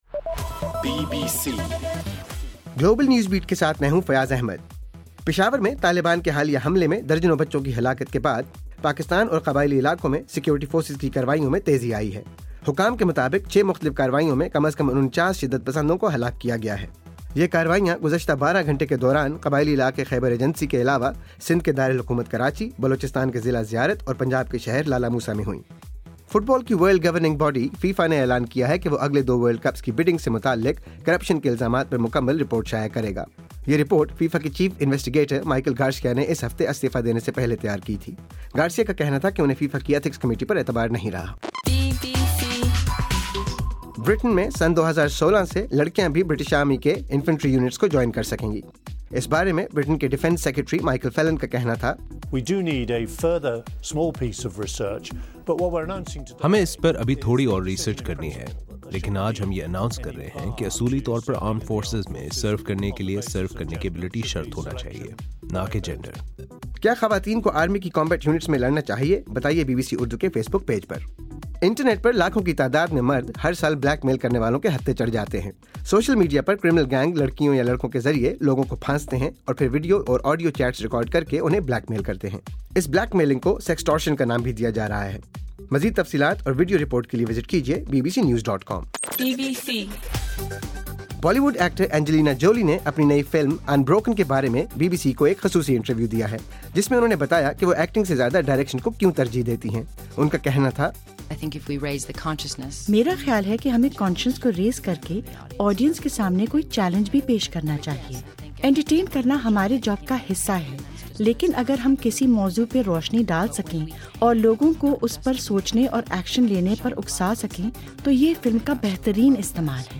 دسمبر 19: رات 10 بجے کا گلوبل نیوز بیٹ بُلیٹن